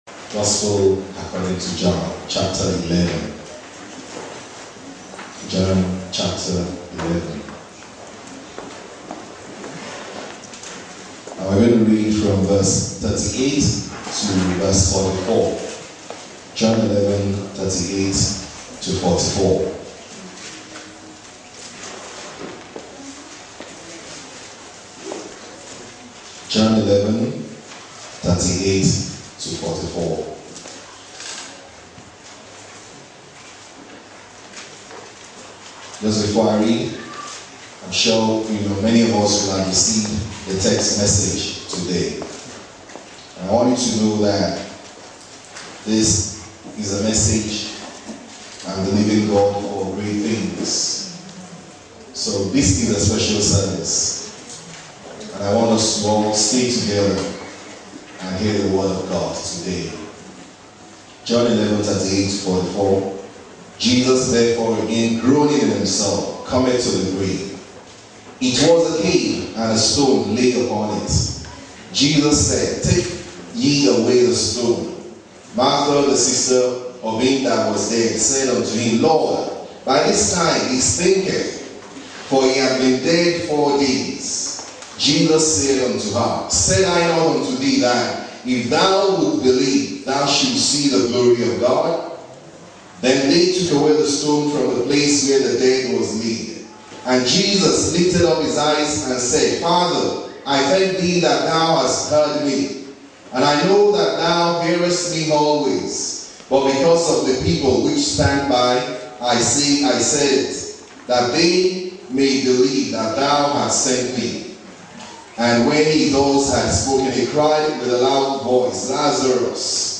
Sabbath Messages